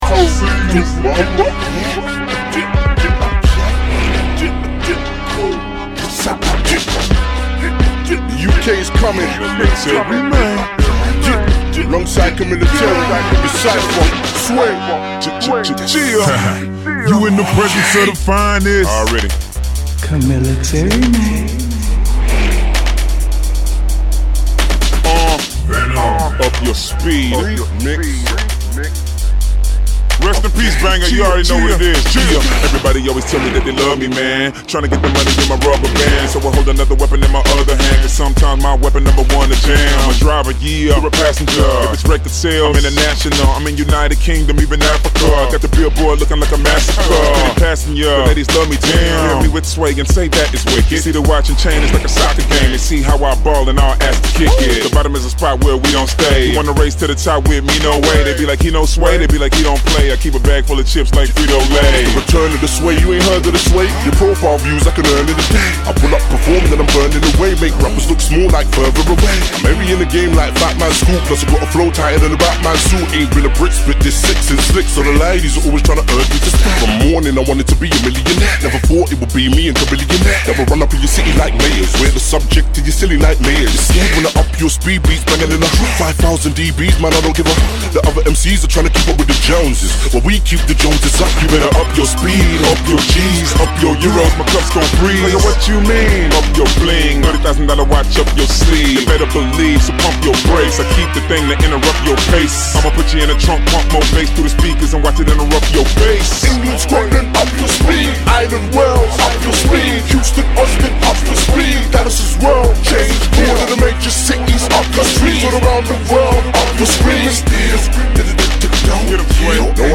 Genre: Screwed & Chopped.